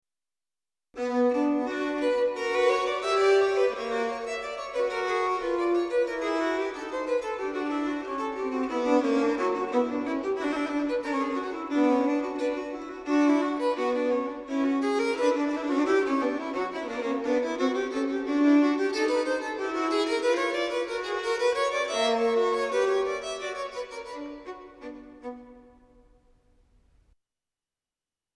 Das akustische Analogon ist ein Kanon aus dem „Musikalischen Opfer“, in dem eine Melodie und ihr Spiegelbild ineinander verwoben sind.